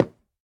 Minecraft Version Minecraft Version latest Latest Release | Latest Snapshot latest / assets / minecraft / sounds / block / cherry_wood / break4.ogg Compare With Compare With Latest Release | Latest Snapshot